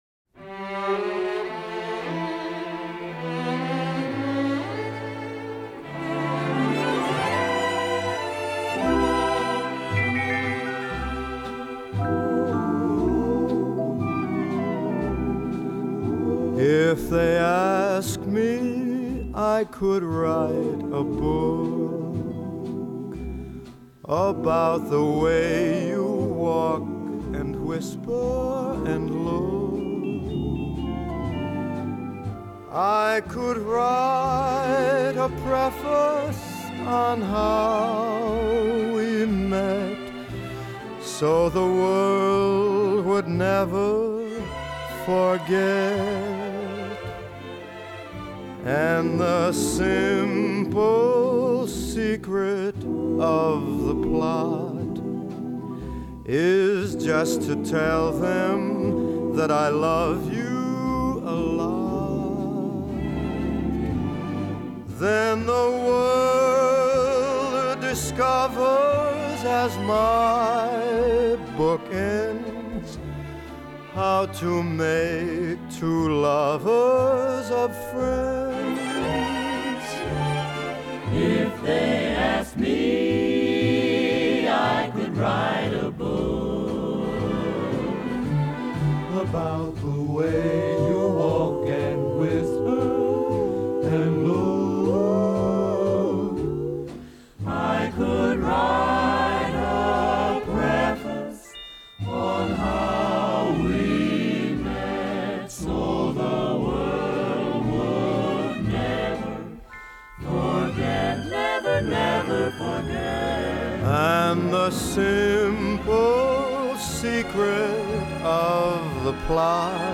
1940   Genre: Musical   Artist